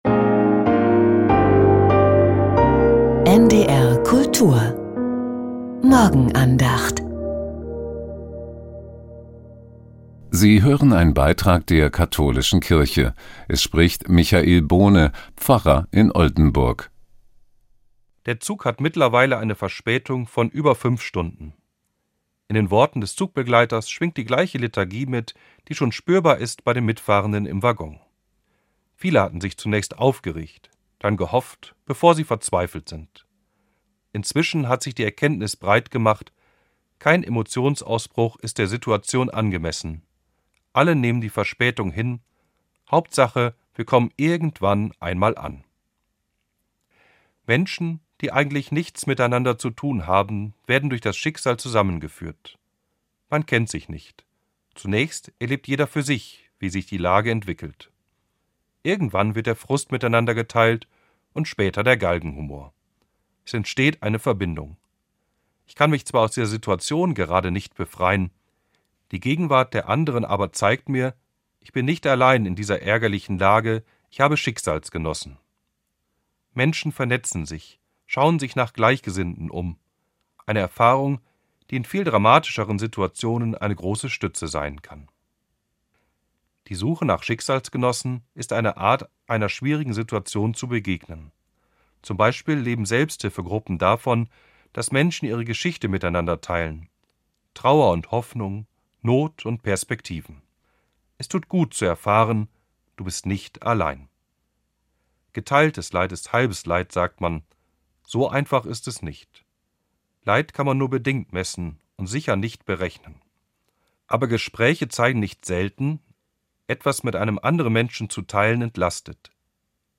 Die Morgenandacht